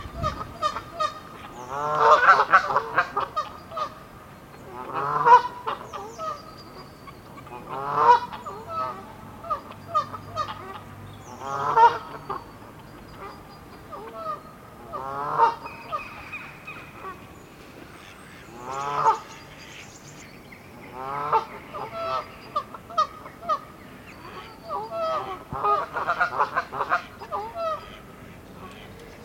grote canadese gans
🔭 Wetenschappelijk: Branta canadensis
grote_canadese_gans_roep.mp3